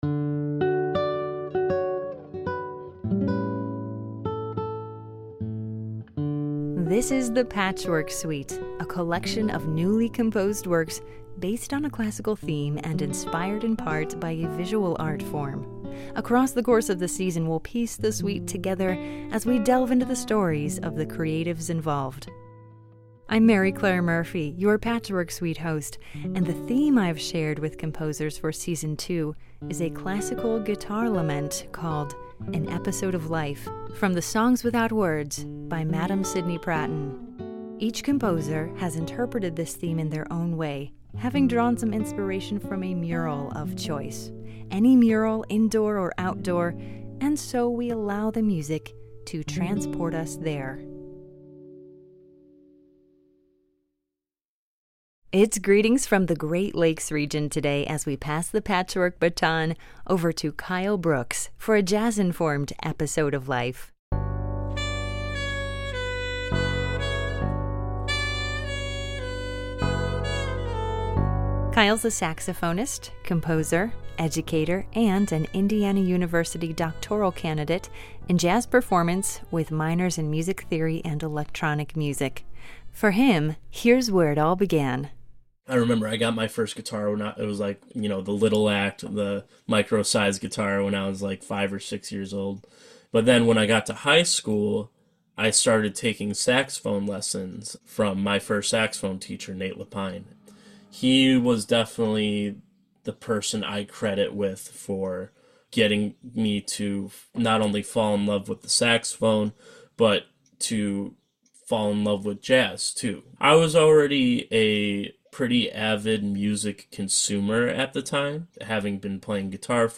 Jazz-informed